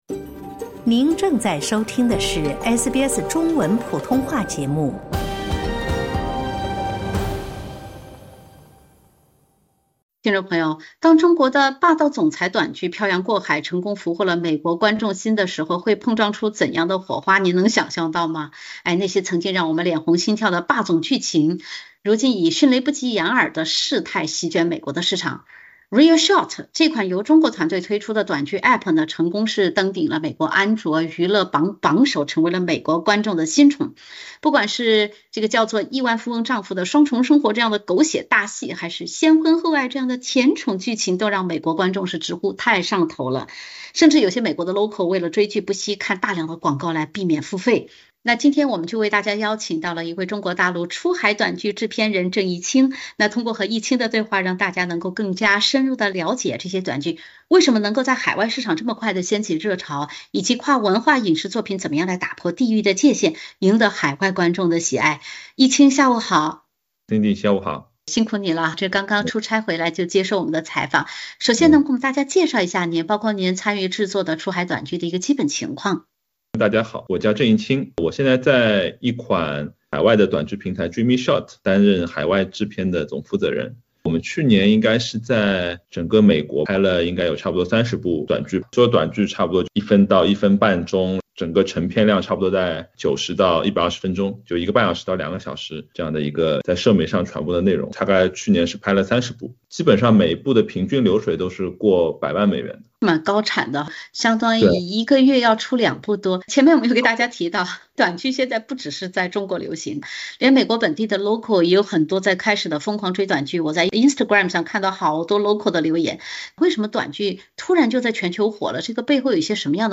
在美国拍摄该类短剧的制片人讲述“霸总剧”为何在海外市场掀起热潮，“爽梗”如何设计，以及西方演员如何理解演绎中国短剧剧本的“梗”。